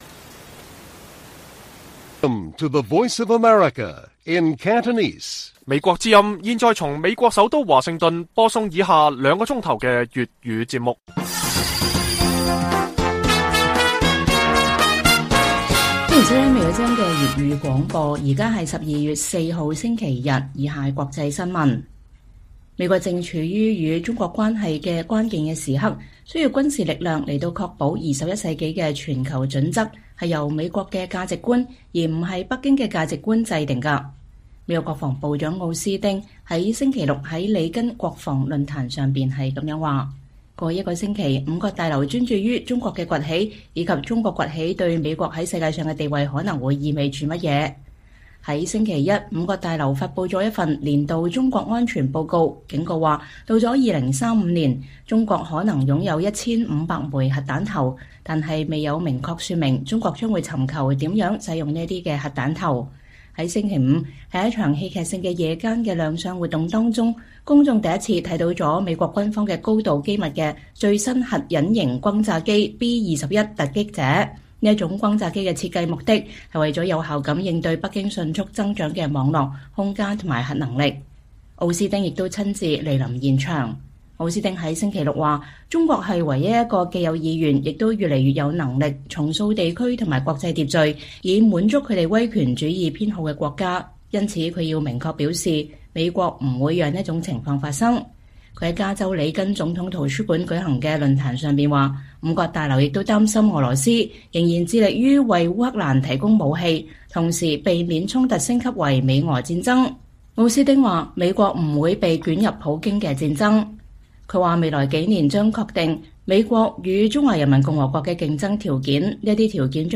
粵語新聞 晚上9-10點：美國防長：美國面臨對抗中國的關鍵時期